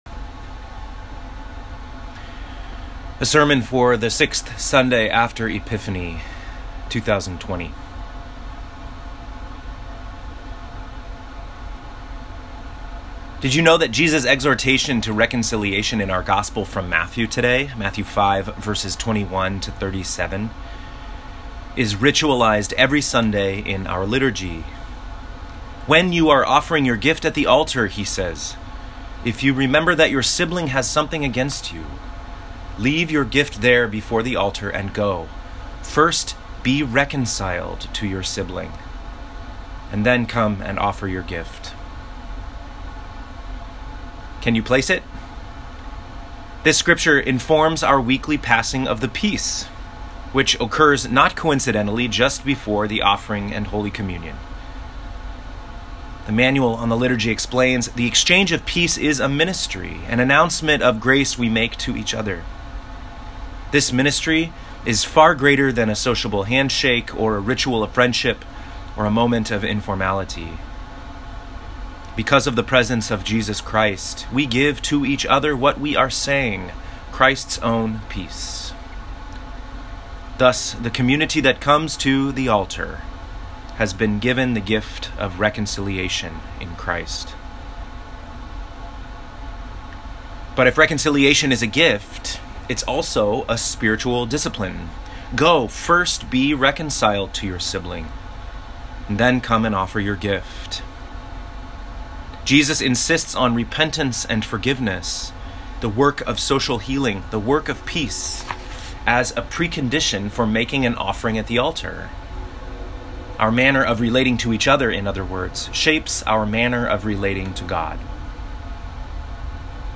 Home › Sermons › Reconciliation